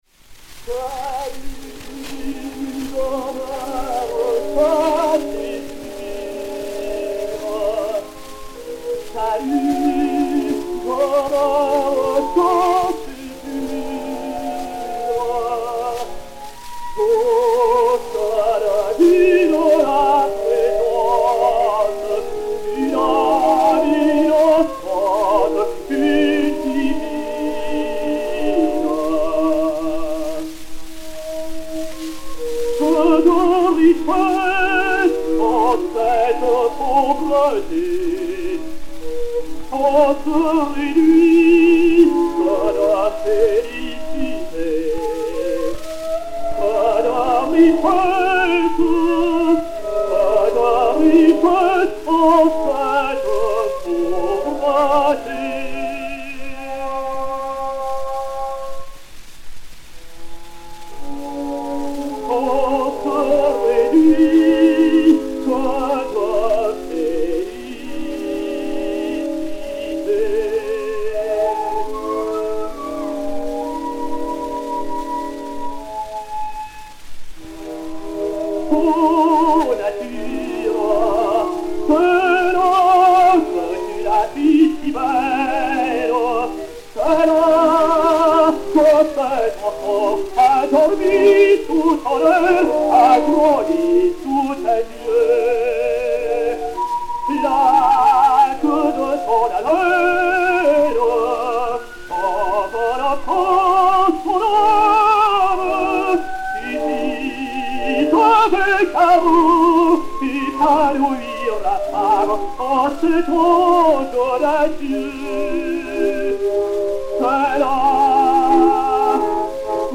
ténor français
Cavatine
Léon Beyle (Faust) et Orchestre
Zonophone X 82494, mat. 4800o, enr. à Paris en 1905